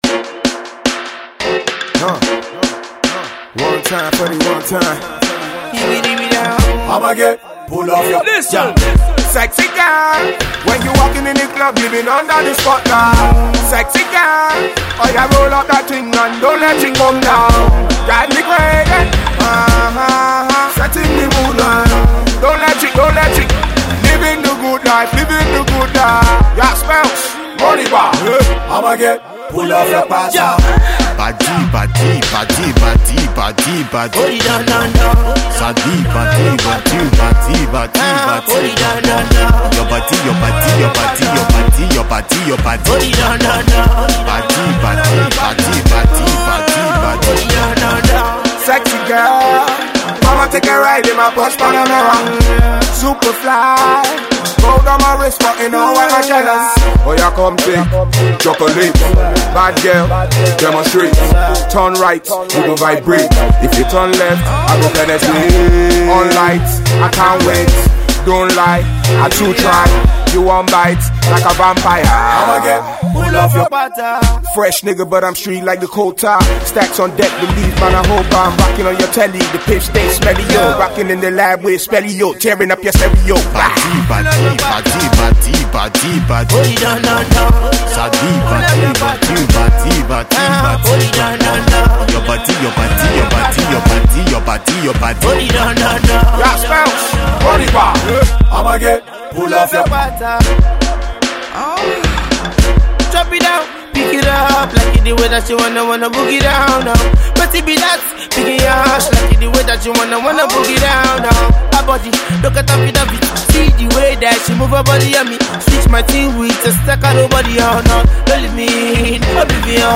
sexy party jam that will get everyone up and dancing
high energy wake up call